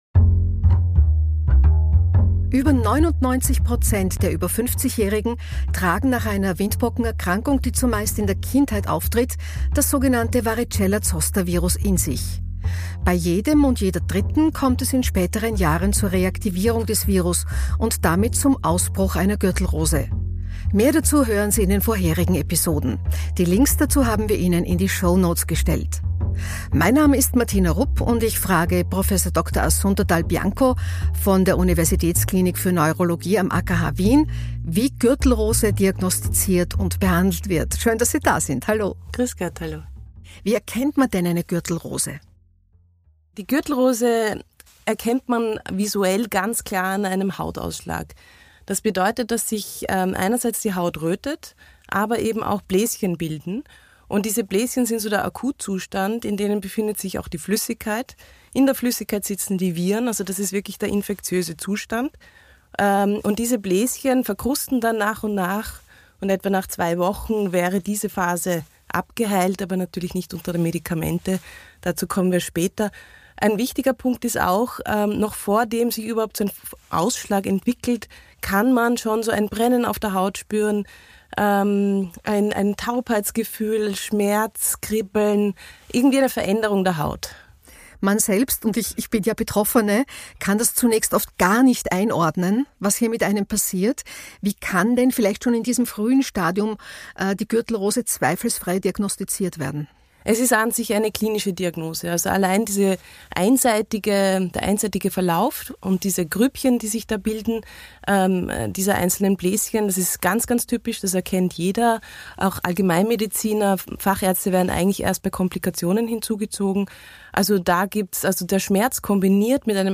Episode von BETRIFFT GÜRTELROSE spricht Moderatorin Martina Rupp